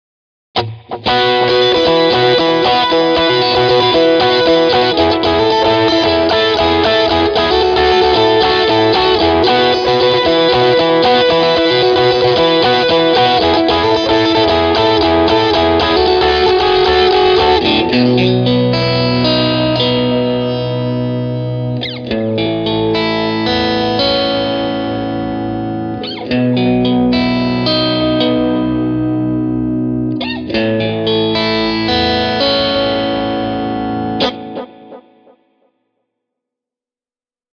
Nämäkin soundit saa BC-2:sta kuulolle helposti ja yllättävän autenttisesti: